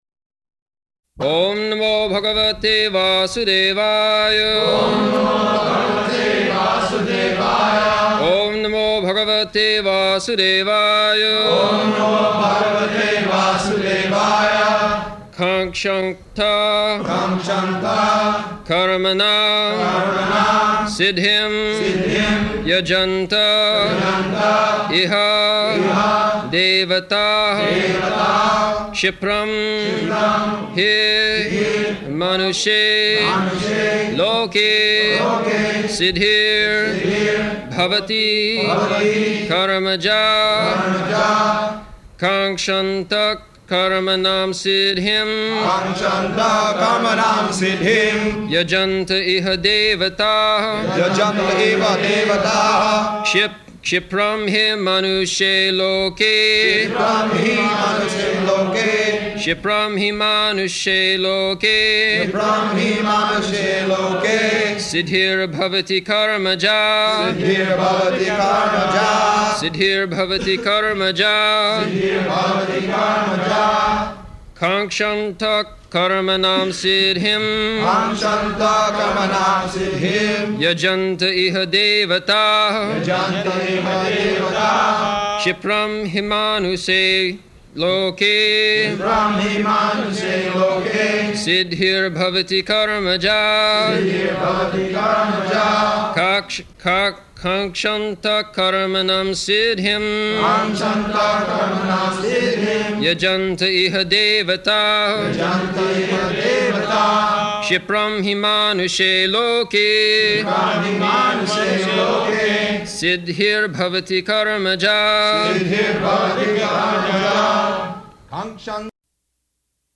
August 4th 1974 Location: Vṛndāvana Audio file
[devotees repeat] [leads chanting of verse, etc.]